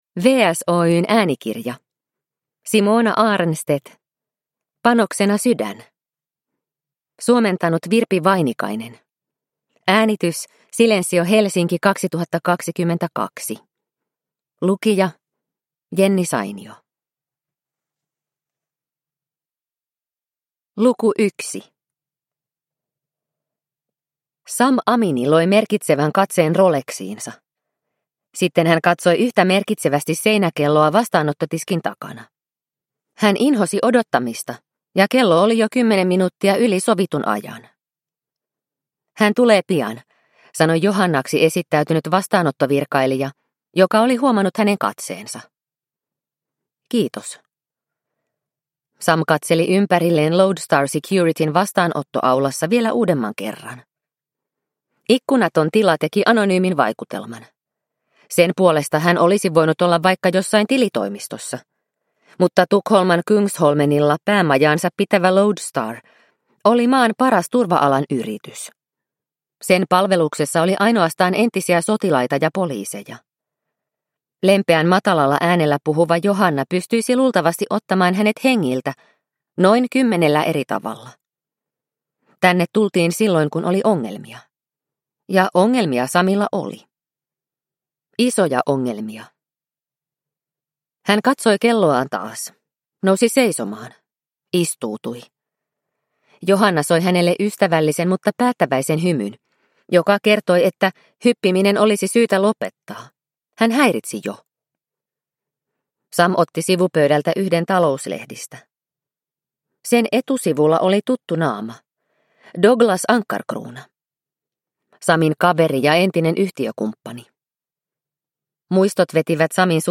Panoksena sydän – Ljudbok – Laddas ner